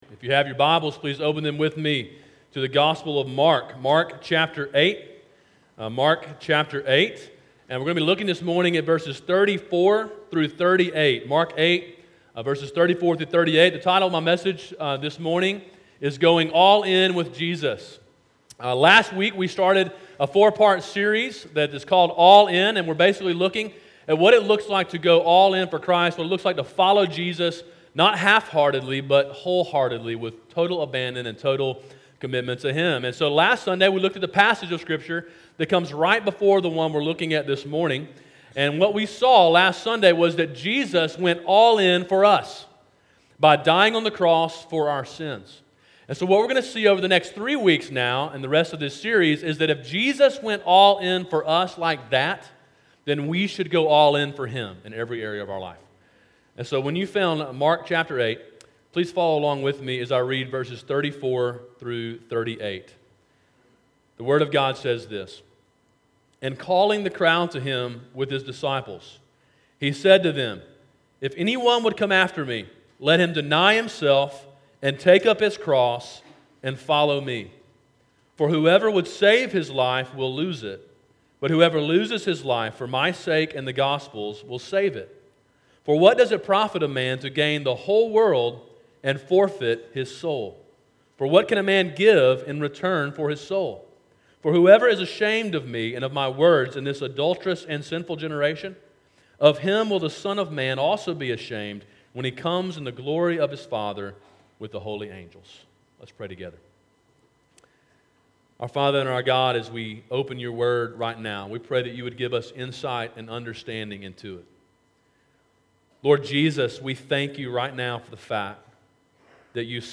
Sermon: “Going All In with Jesus” (Mark 8:34-38)